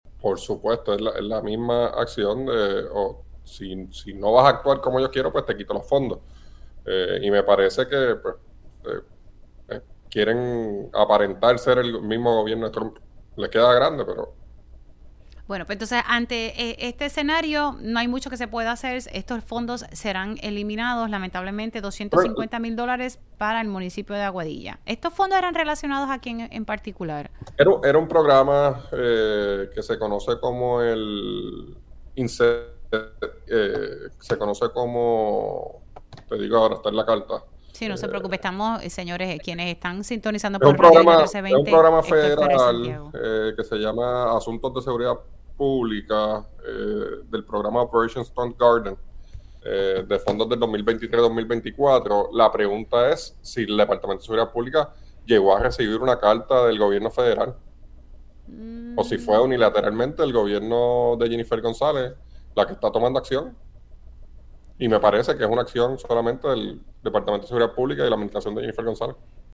El representante Héctor Ferrer Santiago comunicó en este medio que, como medida de represalia, se le denegaron fondos al municipio de Aguadilla.